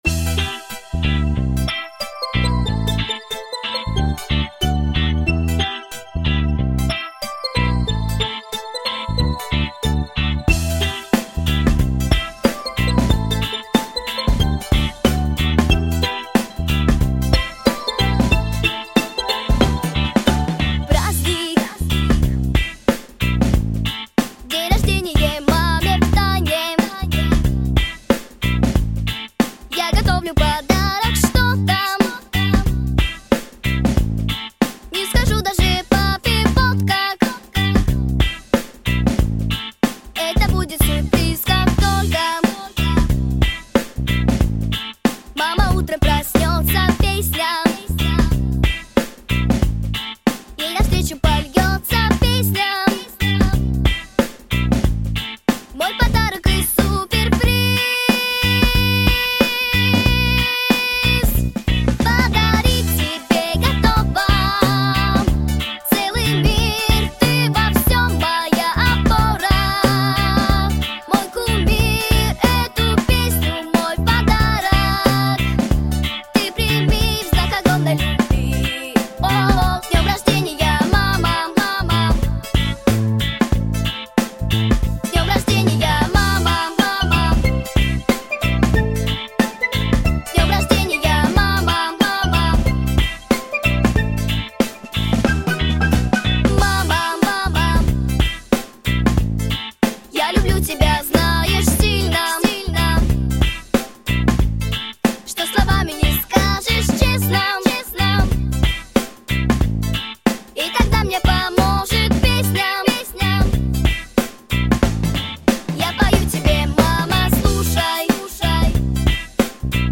🎶 Детские песни / День рождения 🎂